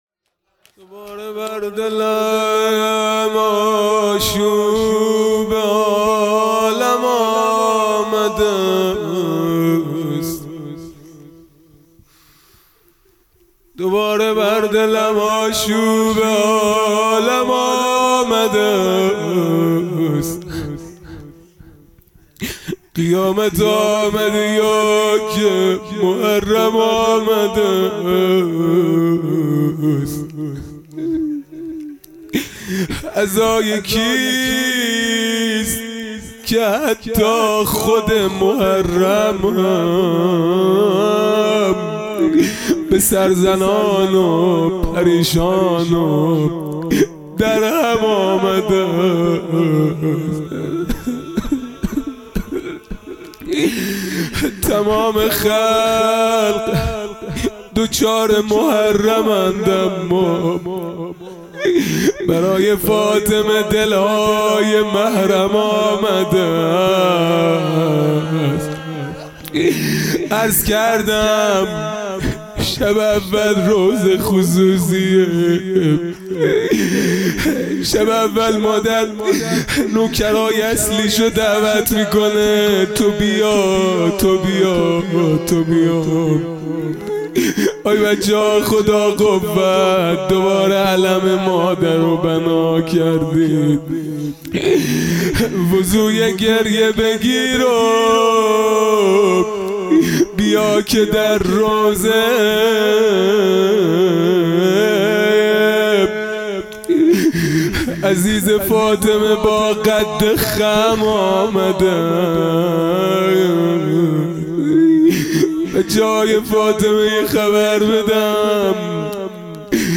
خیمه گاه - هیئت بچه های فاطمه (س) - مناجات پایانی | دوباره بر دلم آشوب عالم آمده است
فاطمیه دوم (شب اول)